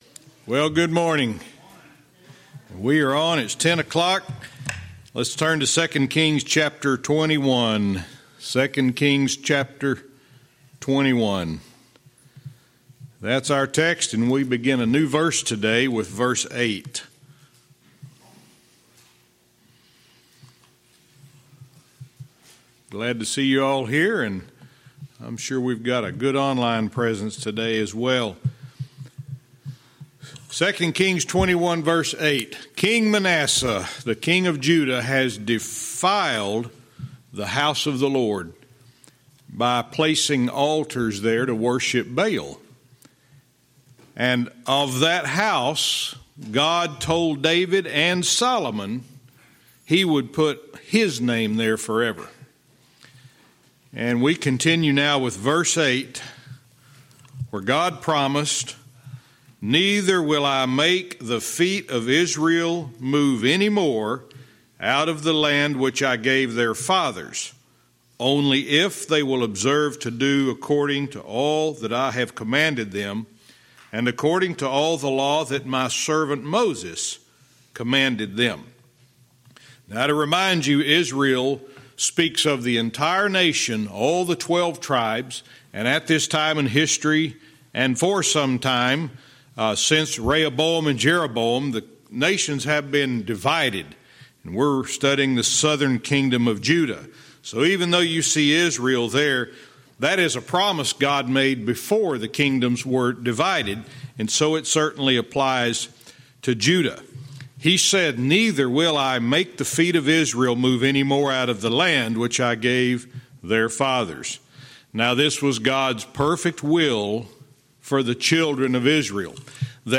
Verse by verse teaching - 2 Kings 21:8-10